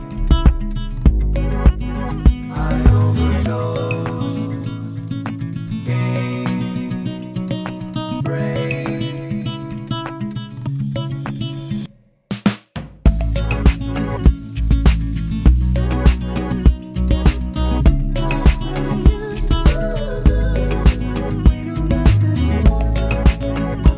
no Backing Vocals Duets 3:41 Buy £1.50